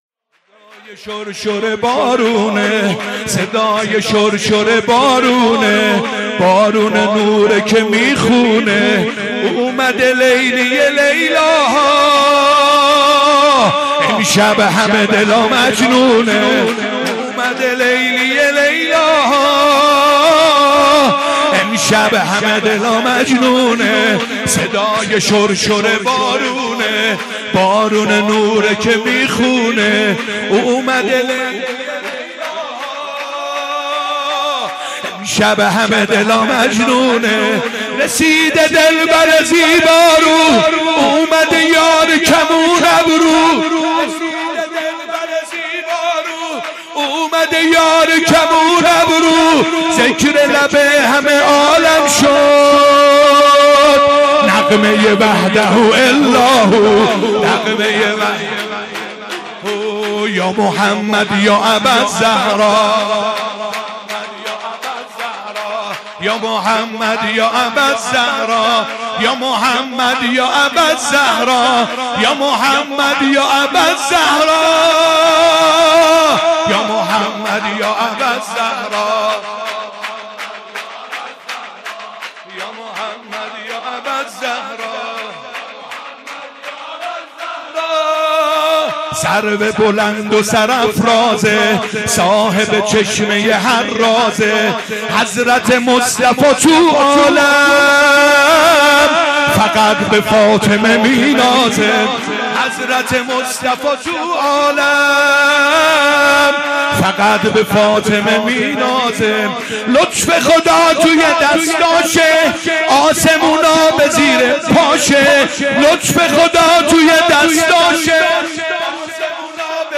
15 آذر 96 - بیت الرضوان - شور - صدای شر شر بارونه
ولادت پیامبر و امام صادق (ع)